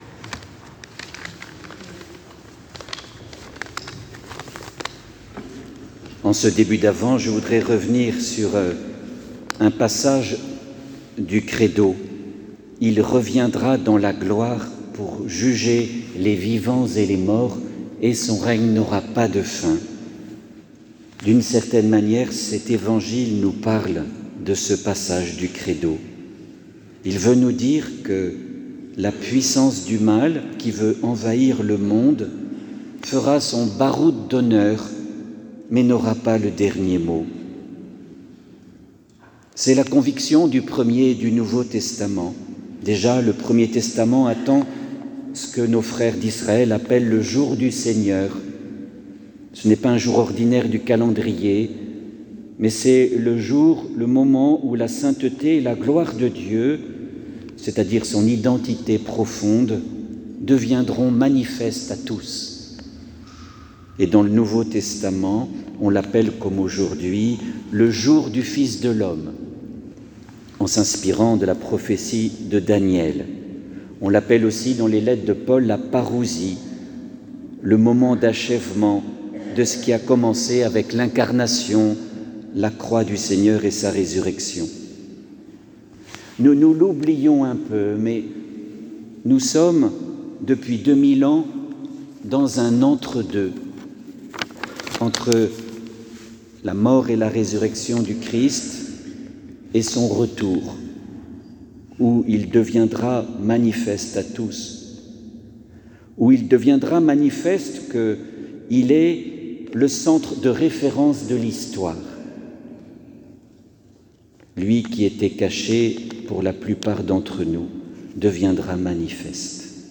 Retrouvez ici un enregistrement audio de l'homélie